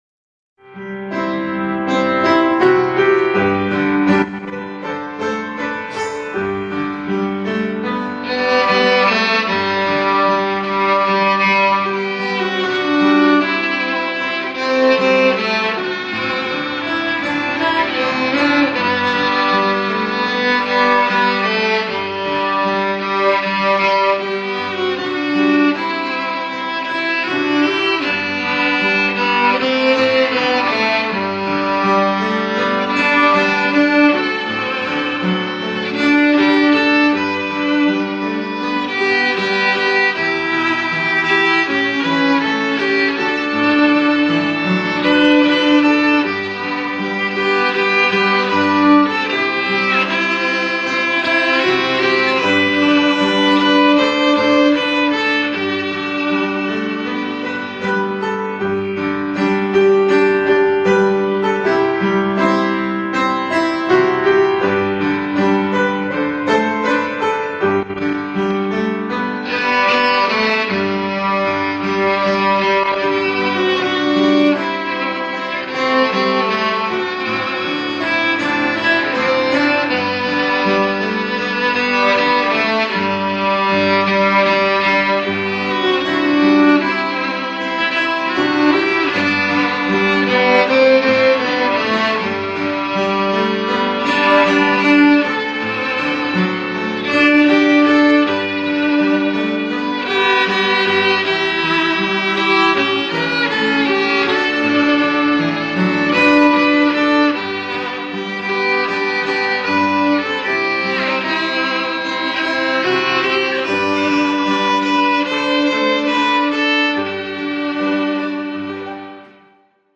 바이올린 연주